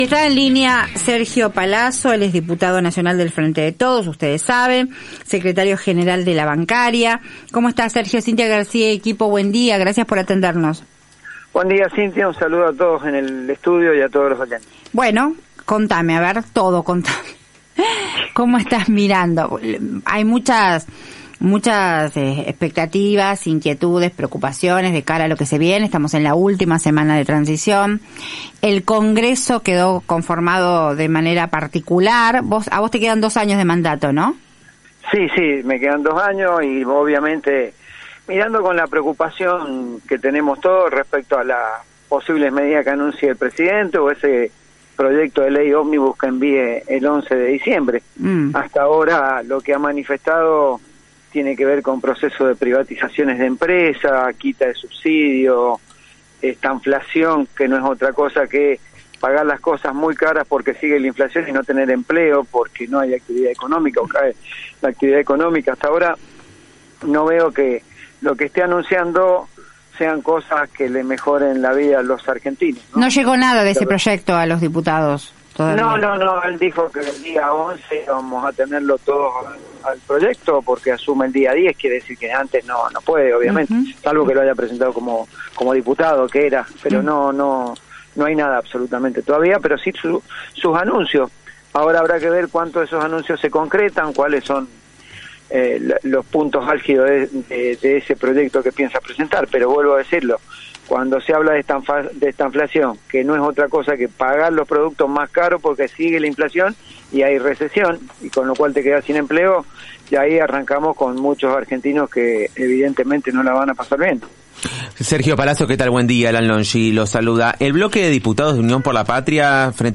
En diálogo con La Garcia por AM750.